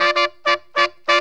HORN RIFF 23.wav